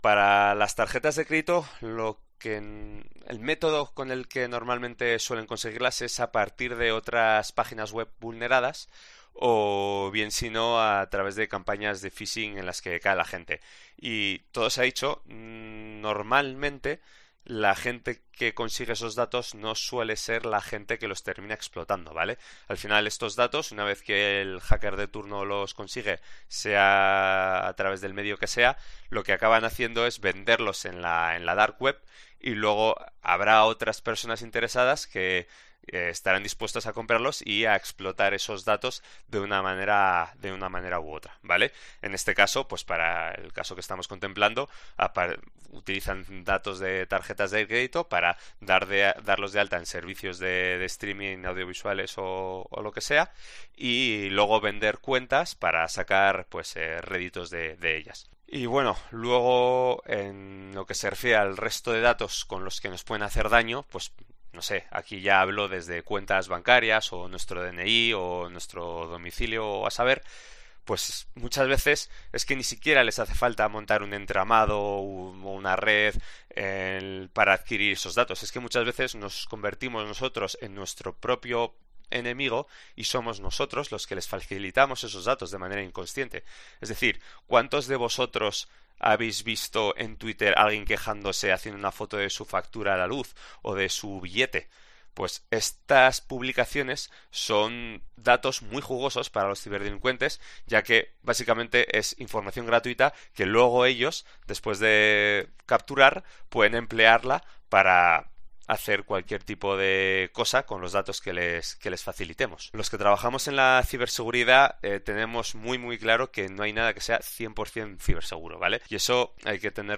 Hay ciertas redes en Internet que comercializan con tus datos bancarios y te suscriben a plataformas de 'streaming' y en COPE hablamos con un experto para indentificarlas